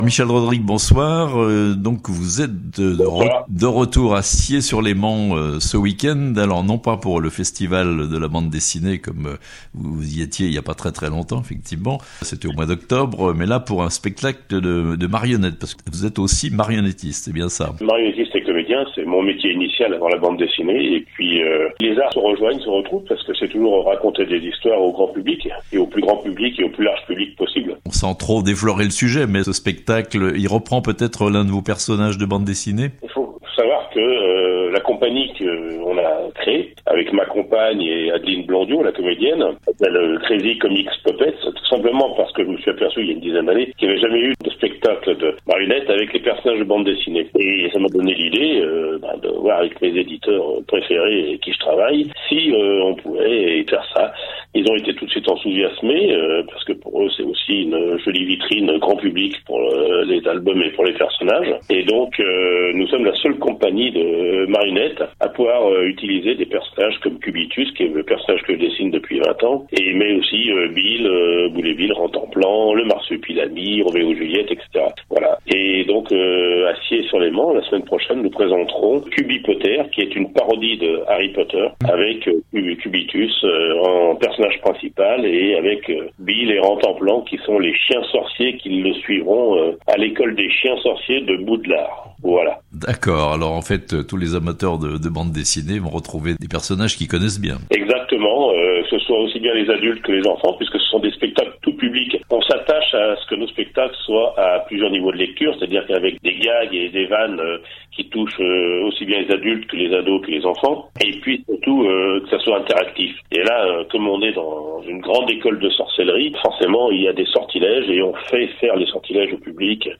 Un spectacle de marionnettes avec des personnages de B.D. (interview)